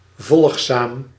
Ääntäminen
US RP : IPA : /ˈdəʊ.sail/ US : IPA : /ˈdɑː.səl/ IPA : /ˈdɑː.saɪl/